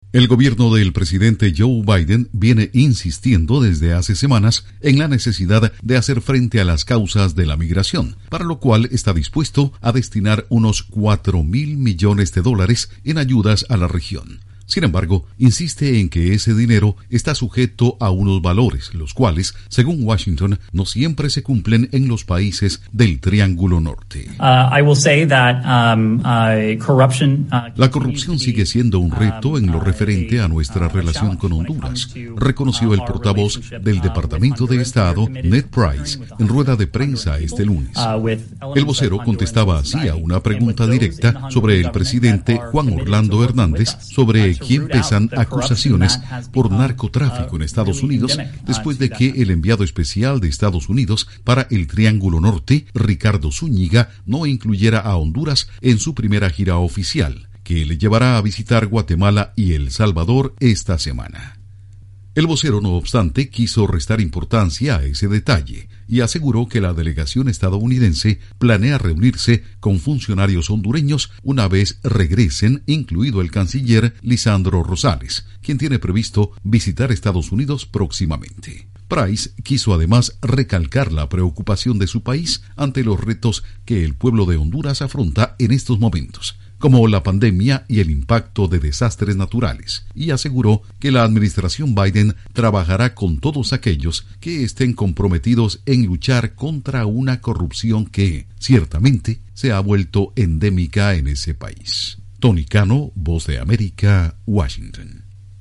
EE. UU. les marca el camino a los presidentes de Honduras y El Salvador. Informa desde la Voz de América en Washington